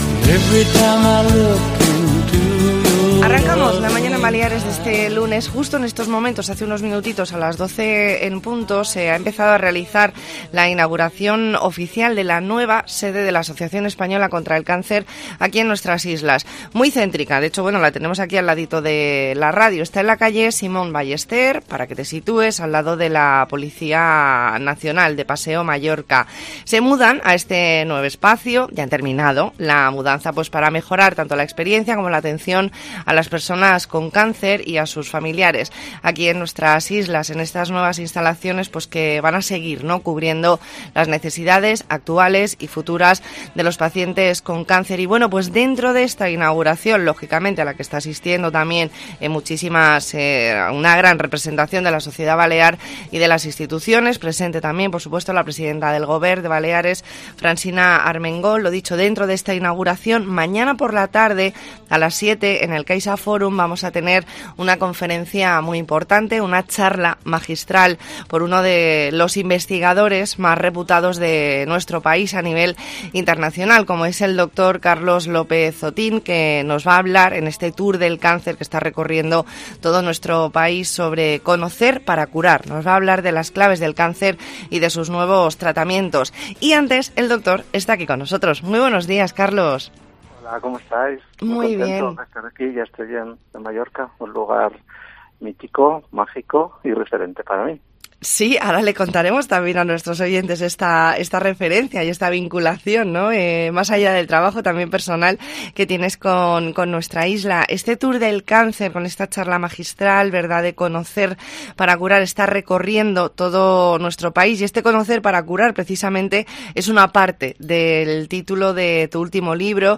Hablamos con doctor Carlos López-Otín, catedrático de Bioquímica y Biología Molecular en la Universidad de Oviedo.
E ntrevista en La Mañana en COPE Más Mallorca, lunes 12 de septiembre de 2022.